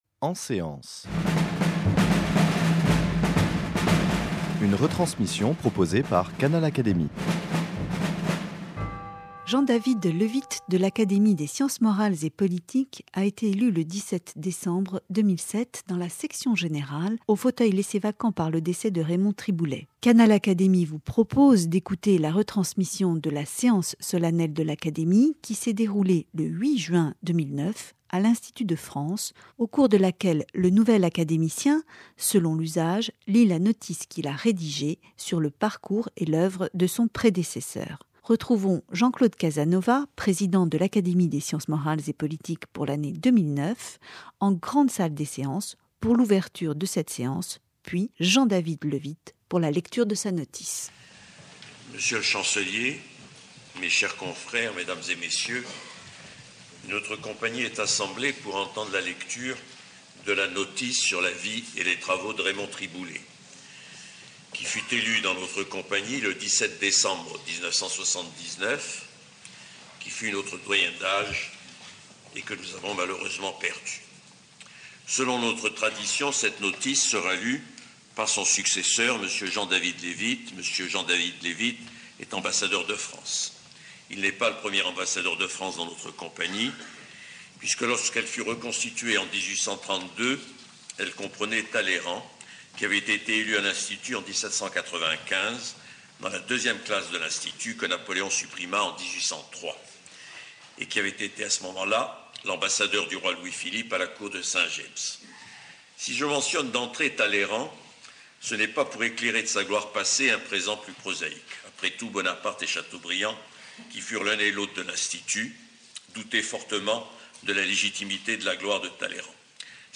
En séance
En ouverture, Jean-Claude Casanova, président de l'Académie pour l'année 2009, rappelle que Raymond Triboulet élu en 1979 a été le doyen d'âge de la compagnie des sciences morales et politiques. Selon la tradition, il invite son successeur Jean-David Levitte, ambassadeur de France , au cours d'une séance solennelle, à lire la notice sur la vie et les travaux de son prédécesseur.